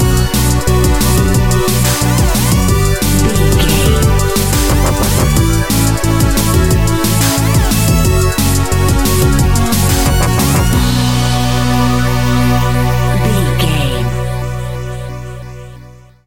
Fast paced
Aeolian/Minor
aggressive
dark
driving
energetic
drum machine
synthesiser
sub bass
synth leads